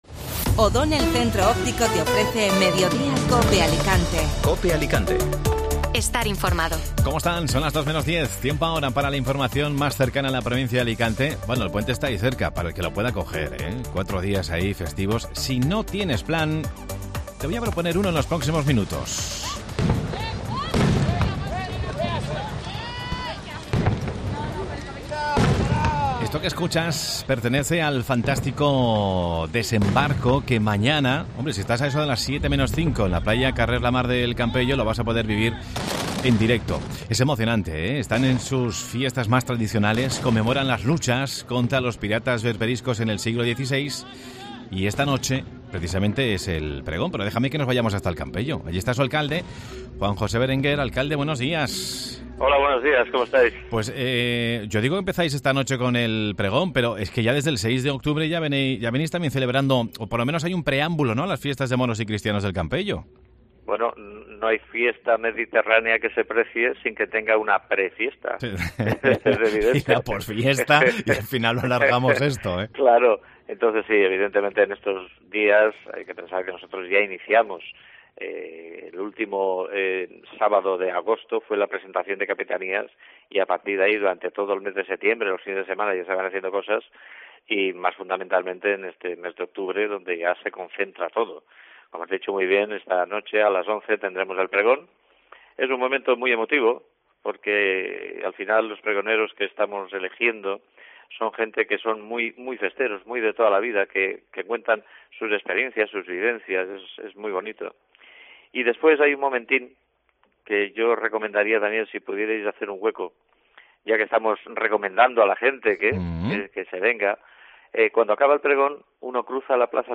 Alicante - Novelda Mediodía COPE Alicante El Campello preparada para sus fiestas más multitudinarias El municipio celebra del 11 al 15 de octubre las fiestas de Moros y Cristianos con un desembarco espectacular como plato fuerte. Escucha la entrevista al acalde Juan José Berenguer